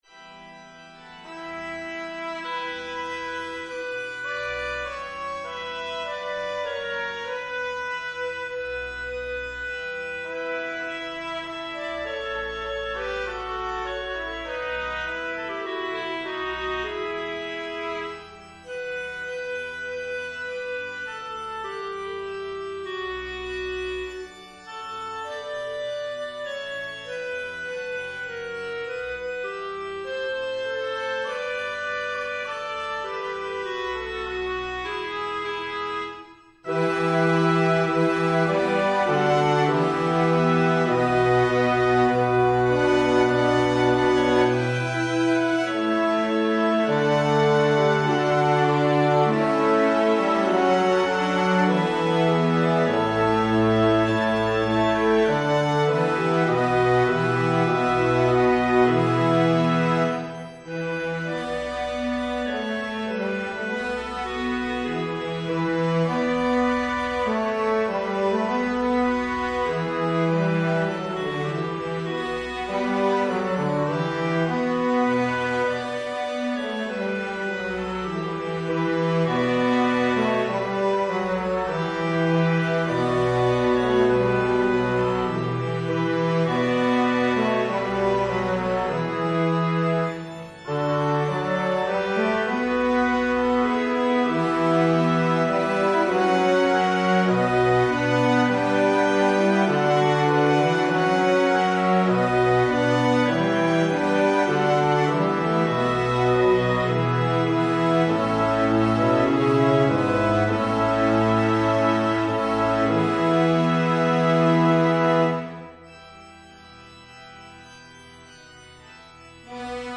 FullScore
Evensong Setting